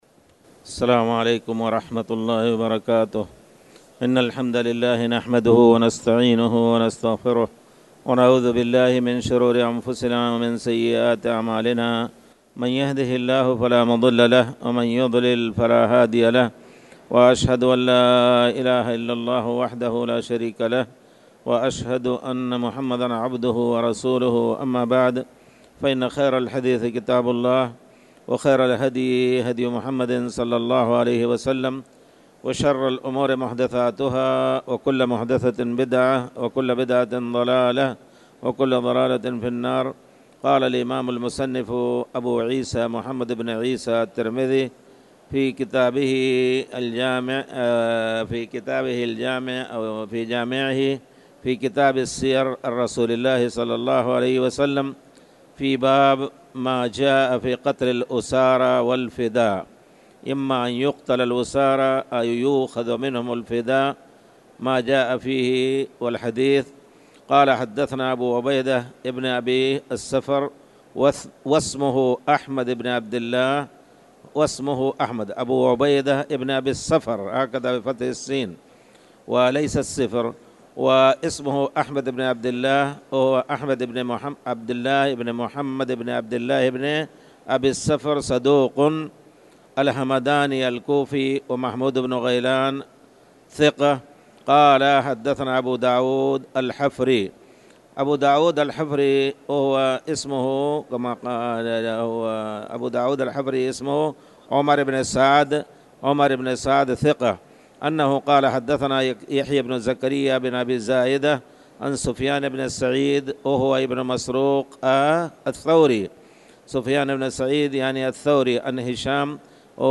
تاريخ النشر ١٦ رجب ١٤٣٨ هـ المكان: المسجد الحرام الشيخ